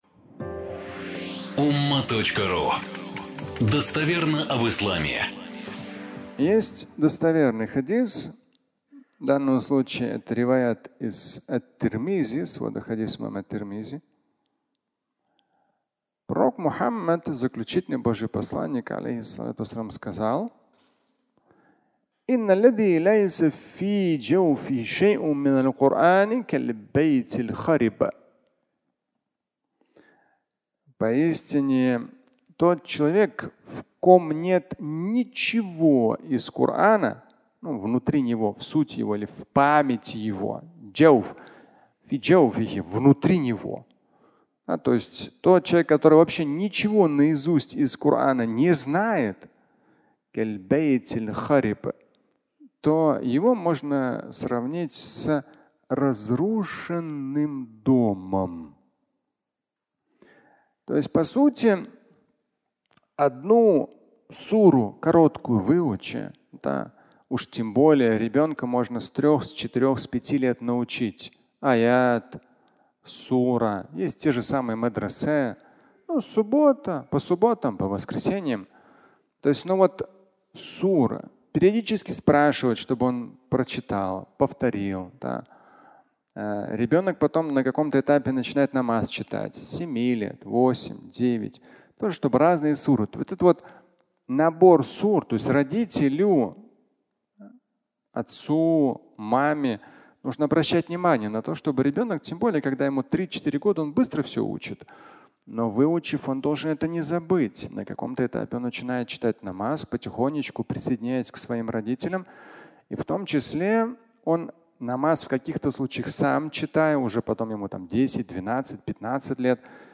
Выучи суру (аудиолекция)